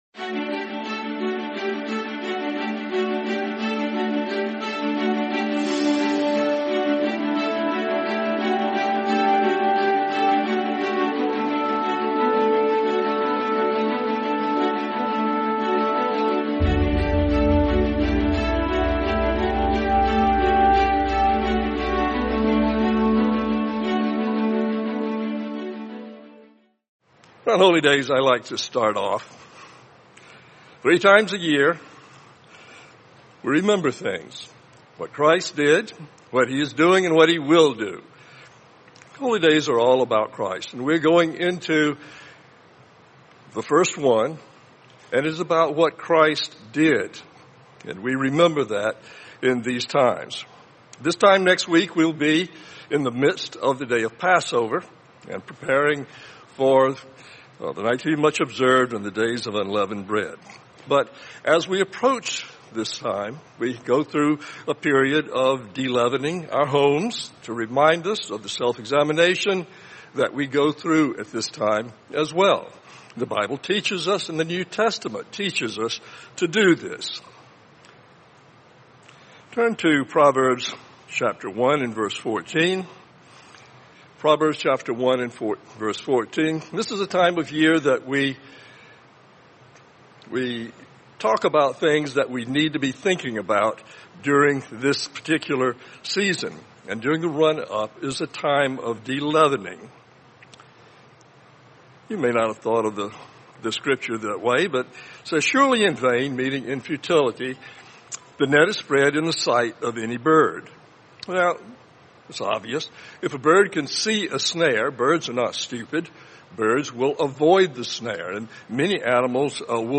Sermon The Leaven of Secret Sin